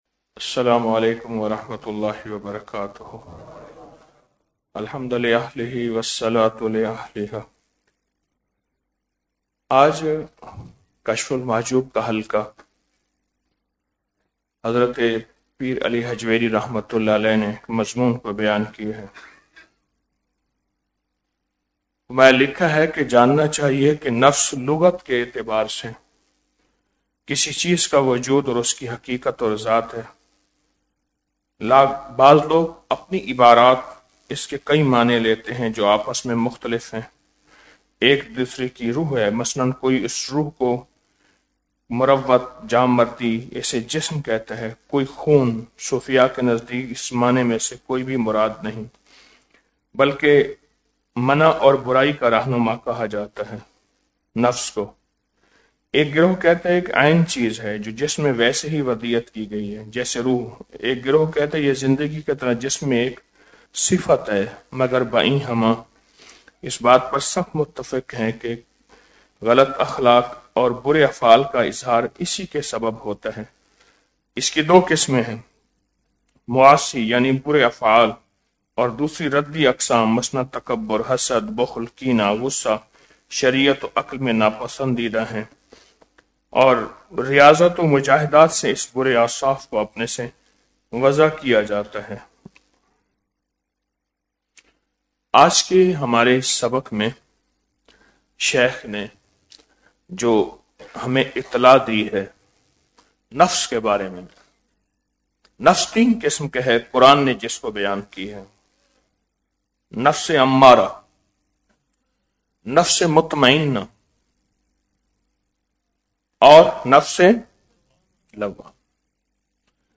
04 رمضان المبارک بعد نماز فجر- 22 فروری 2026ء